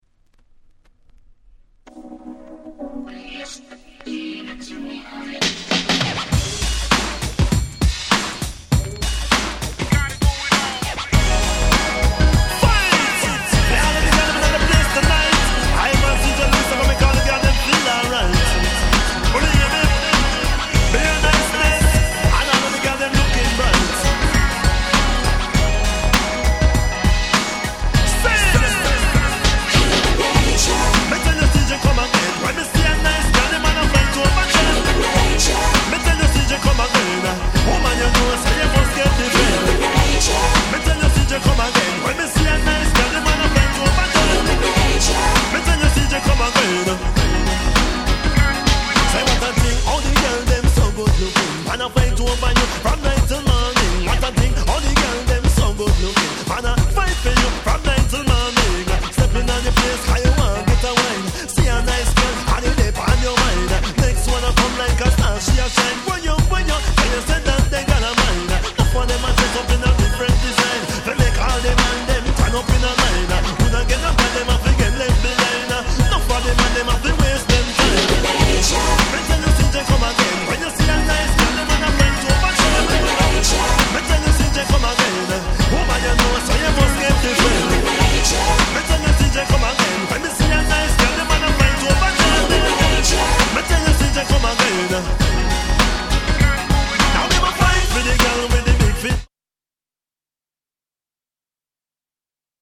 コレは完全にR&Bでしょ。